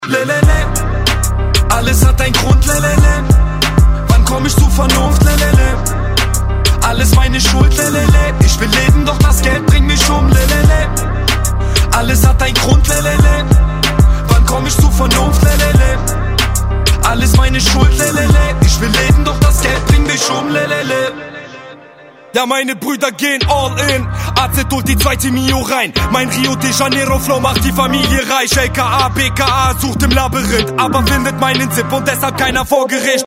Рэп рингтоны